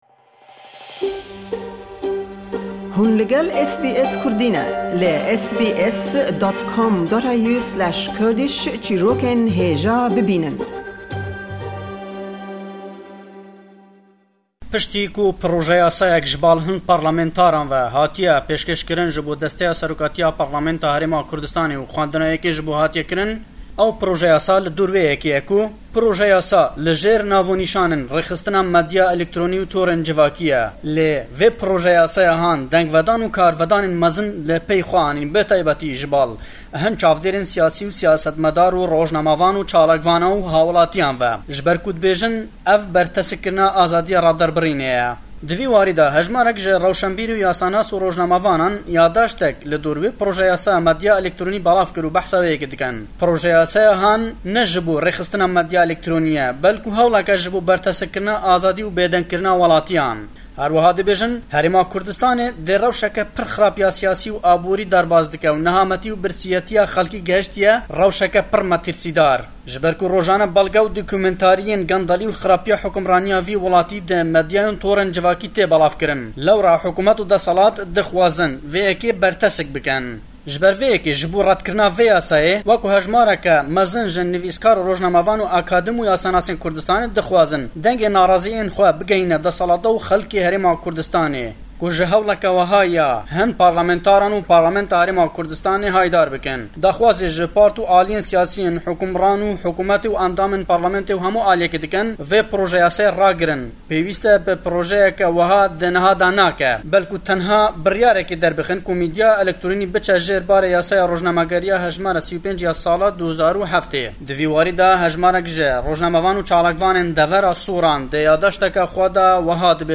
Le em raporte da le Hewlêre we